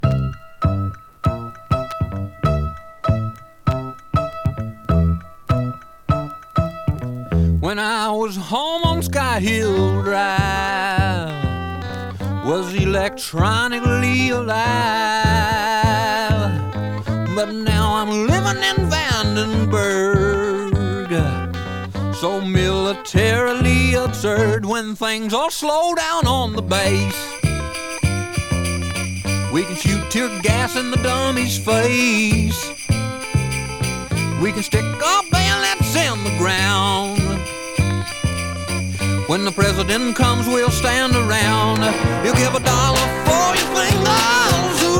Rock, Psychedelic, Symphonic　USA　12inchレコード　33rpm　Stereo